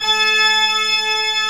Index of /90_sSampleCDs/AKAI S6000 CD-ROM - Volume 1/VOCAL_ORGAN/CHURCH_ORGAN
ORG D4MF  -S.WAV